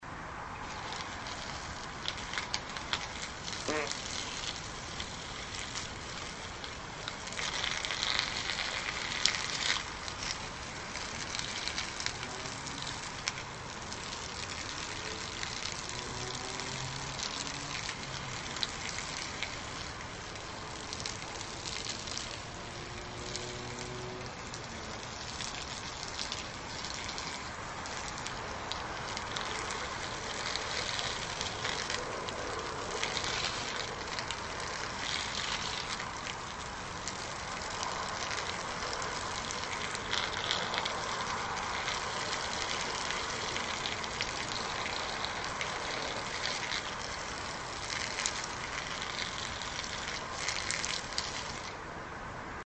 Атмосфера подземной парковки: просторное внутреннее пространство, тихое эхо, редкие люди, металлические двери, насос для шин и случайные птицы